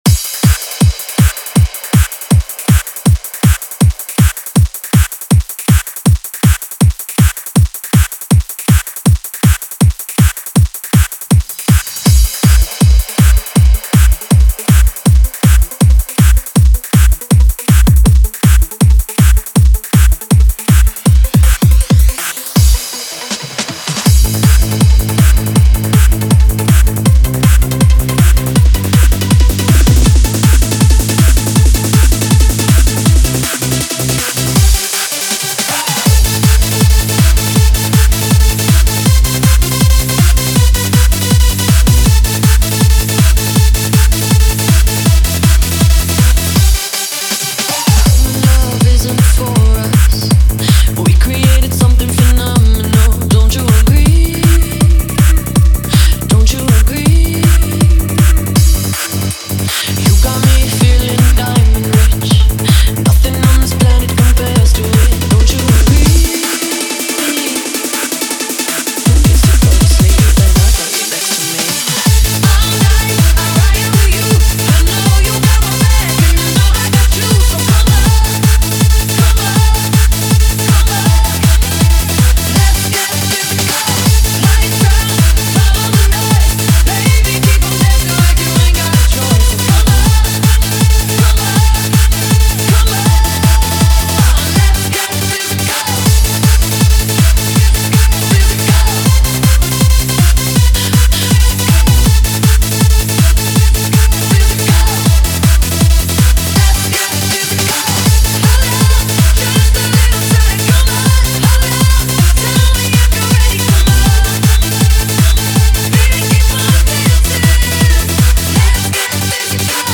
Género: Hard Dance.